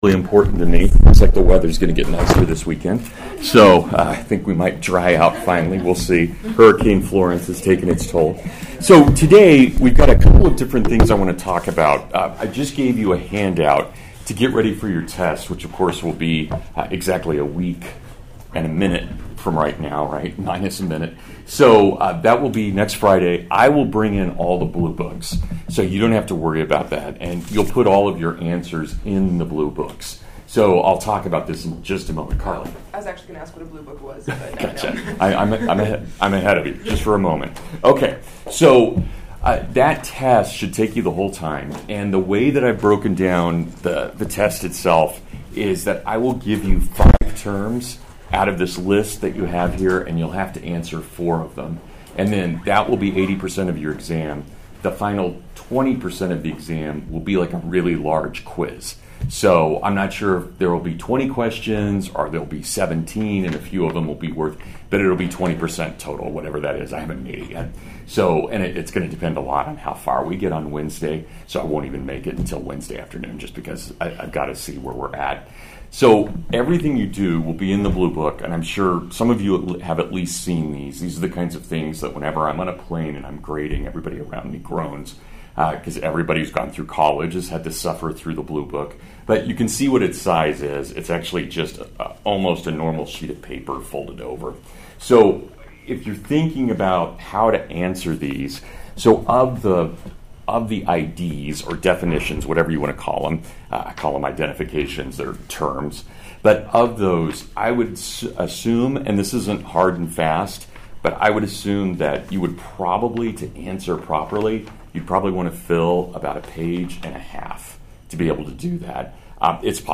Maccabees (Full Lecture)